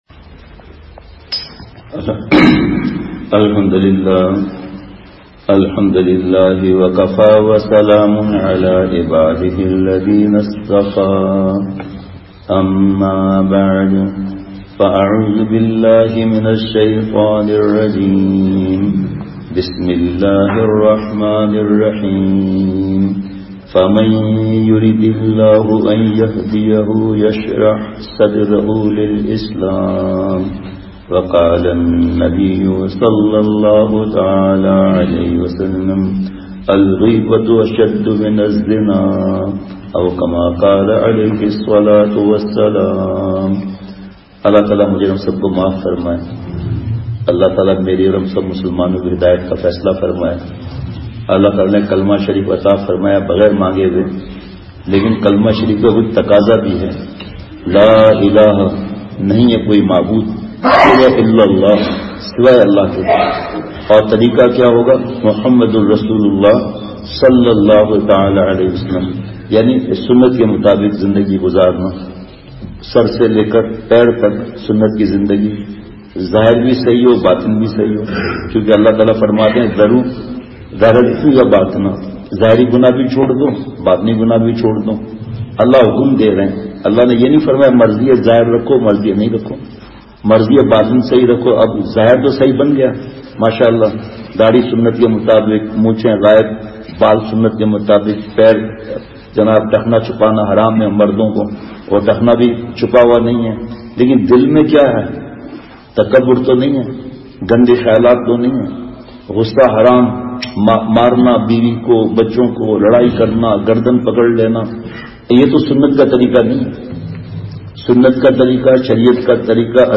بمقام :۔ مدنی مسجد حبیب آباد۔پشاور بیان